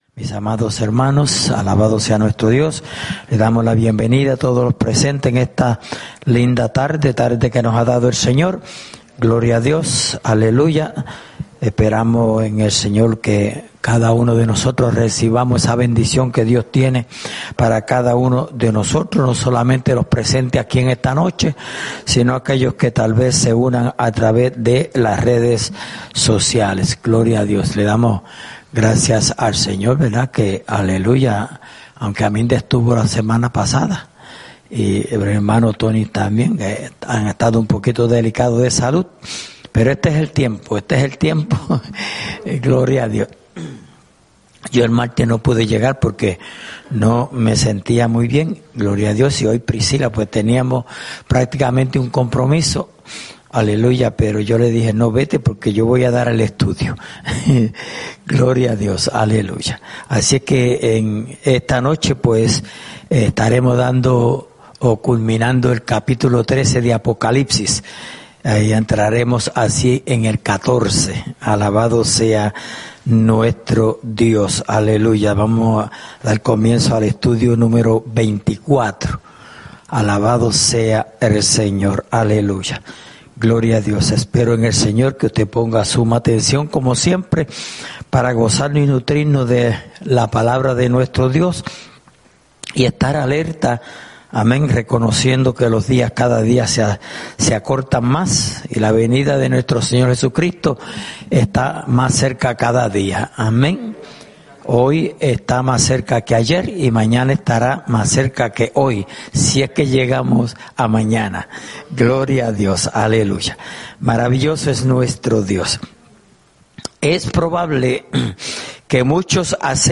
Estudio Bíblico: Libro de Apocalipsis (Parte 24)